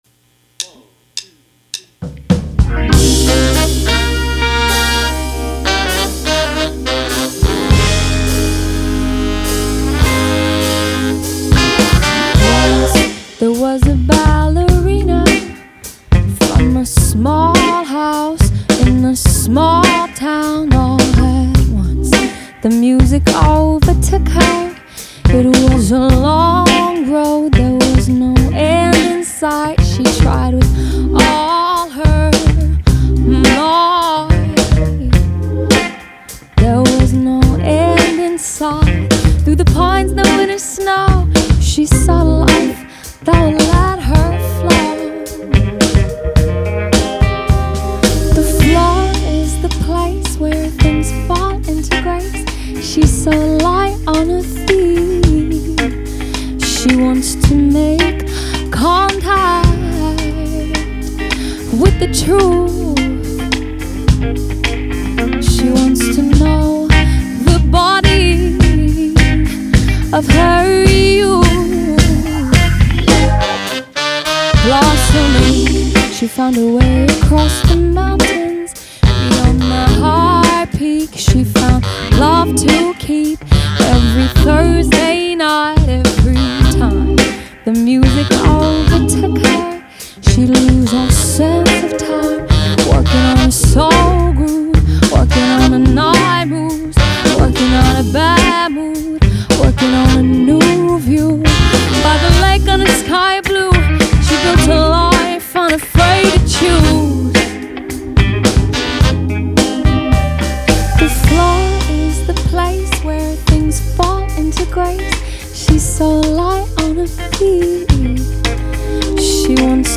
Hier mal ein Ozone Quick Master von mir, habe die erste File vom Post genommen, die Stimme gefällt mir nicht so richtig, müsste mich länger dransetzen um genauere Korrekturen vorzunehmen, bei 1k-5k ist ziemlich viel Alarm in deinem Rough Mix...